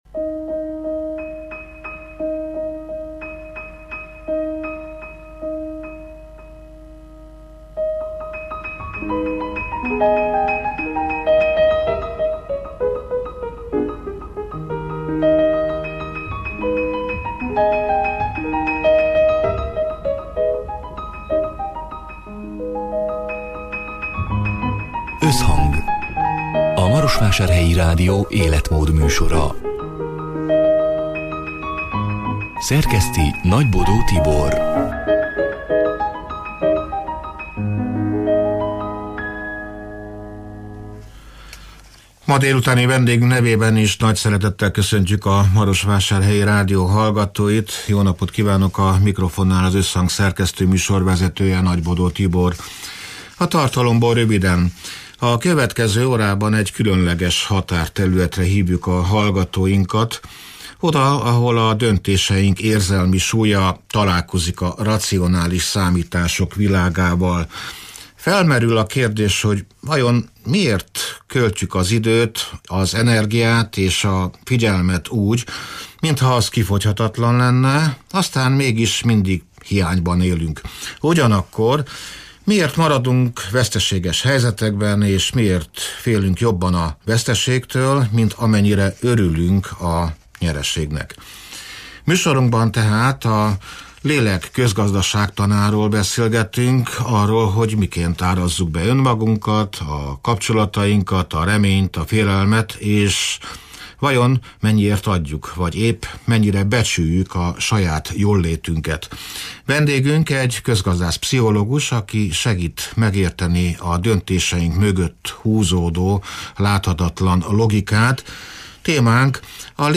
A Marosvásárhelyi Rádió Összhang (elhangzott: 2026. január 28-án, szerdán délután hat órától élőben) című műsorának hanganyaga: